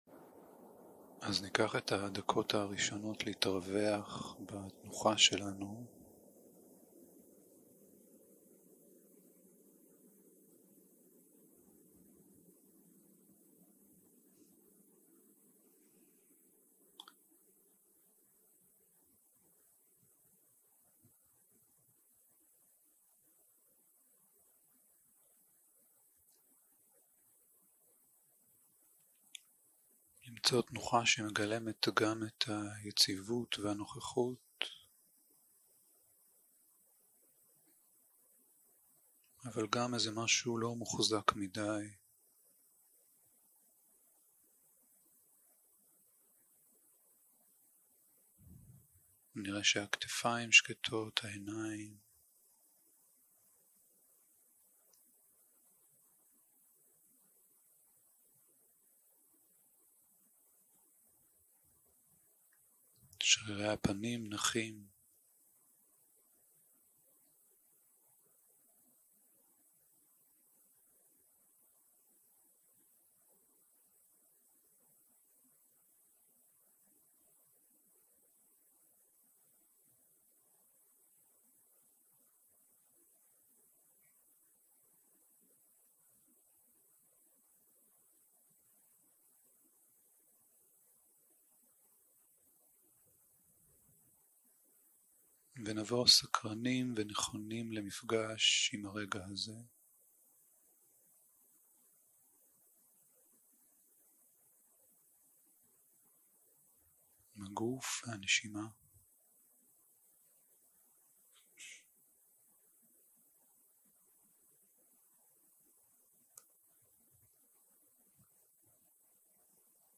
יום 2 - הקלטה 3 - בוקר - מדיטציה מונחית
Guided meditation